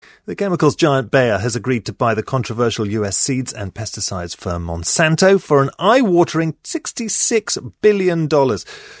【英音模仿秀】拜耳天价收购孟山都 听力文件下载—在线英语听力室